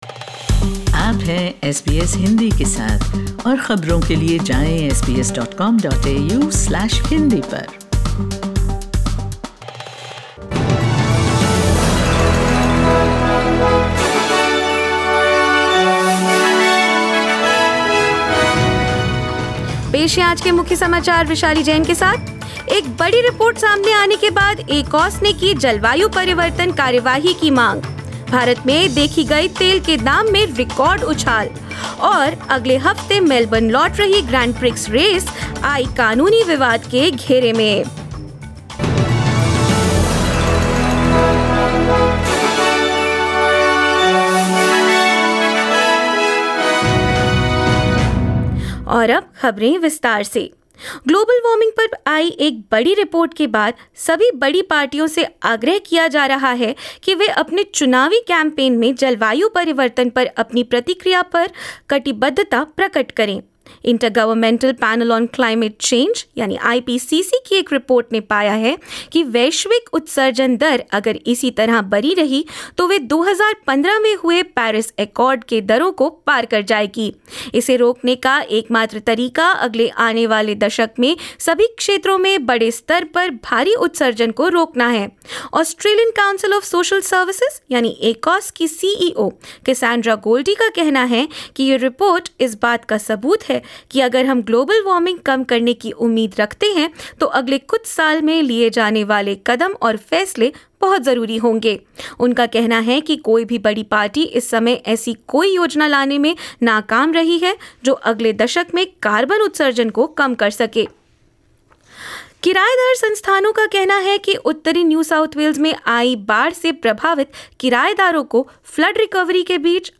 In this latest Hindi bulletin: Major parties have been called upon to commit to action on climate change in their respective election campaigns, following the release of the latest IPCC report on global warming; India records 13 fuel price surges in a fortnight; The Grand Prix sued over a cancelled concert as the race returns to Melbourne this weekend and more news.